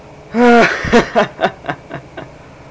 General Laughter Sound Effect
Download a high-quality general laughter sound effect.
general-laughter.mp3